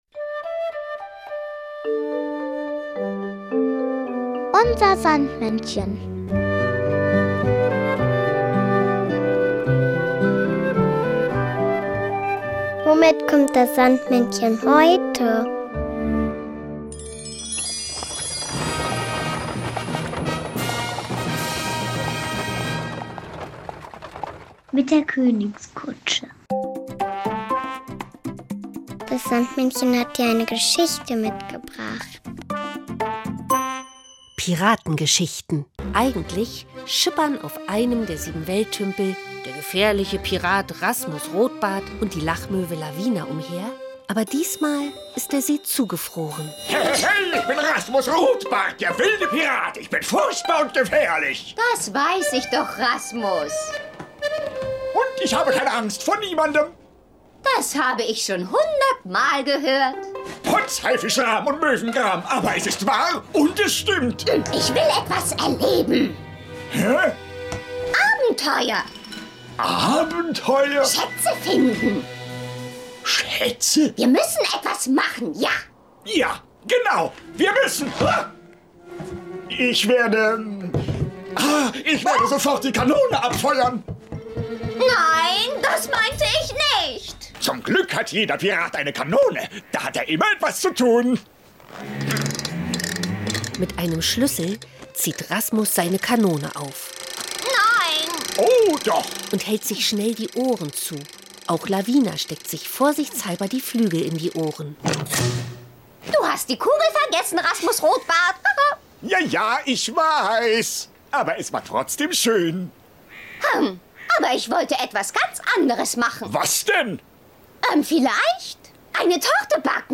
Unser Sandmännchen: Geschichten und Lieder 2 ~ Unser Sandmännchen Podcast